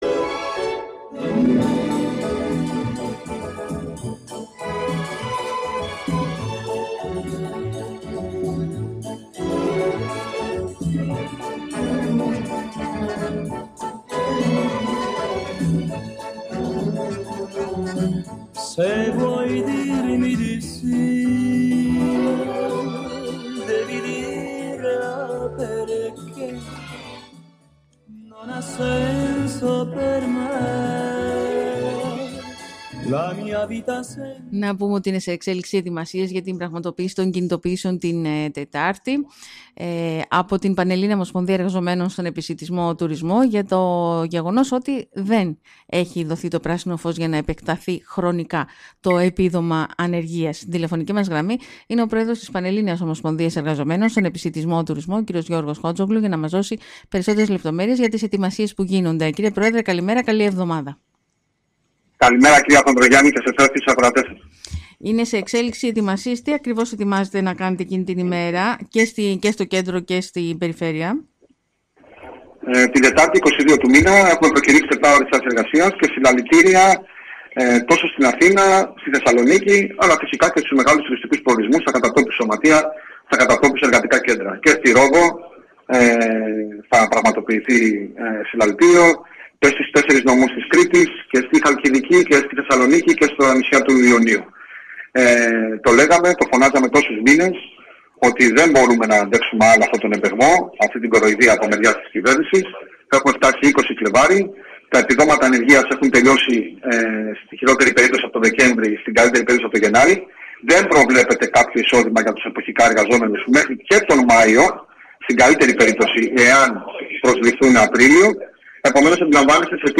Στο θέμα αυτό αναφέρθηκε μιλώντας σήμερα στον Sky